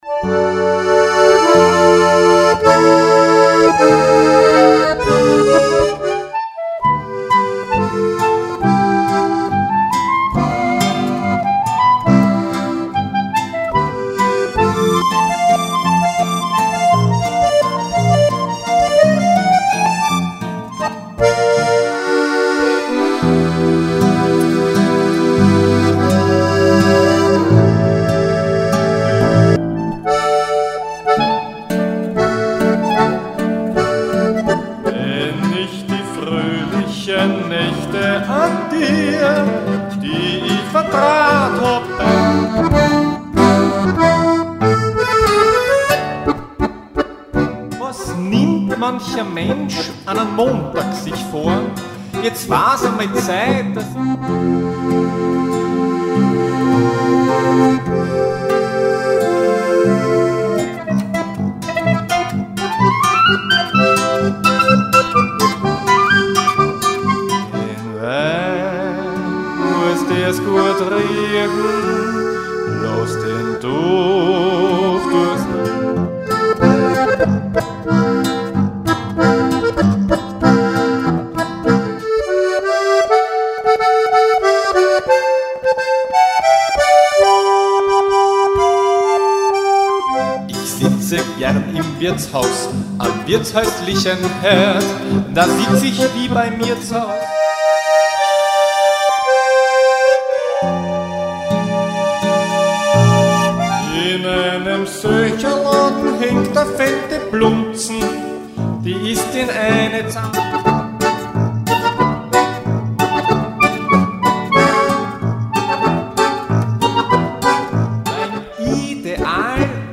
Harmonika, Klarinette und Kontragitarre
Hier ist ein Querschnitt durch die CD